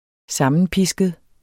Udtale [ -ˌpisgəð ]